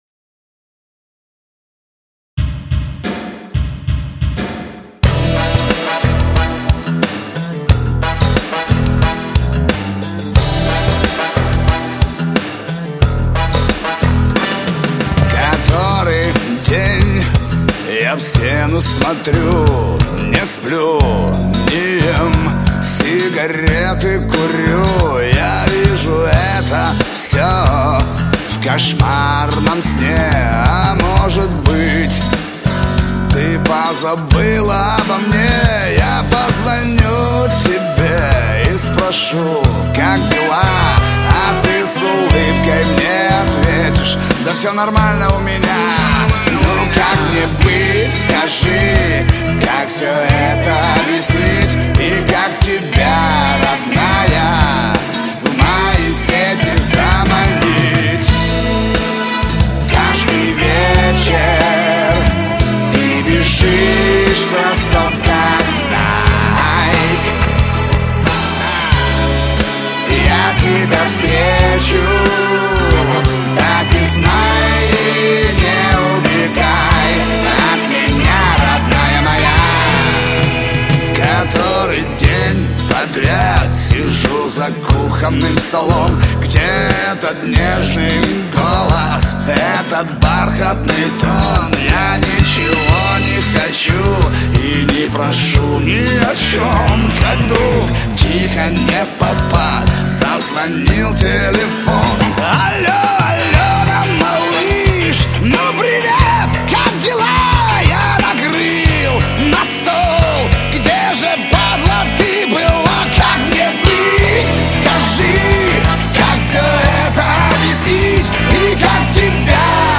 Эстрада, шансон